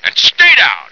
flak_m/sounds/male2/int/M2staydown.ogg at 86e4571f7d968cc283817f5db8ed1df173ad3393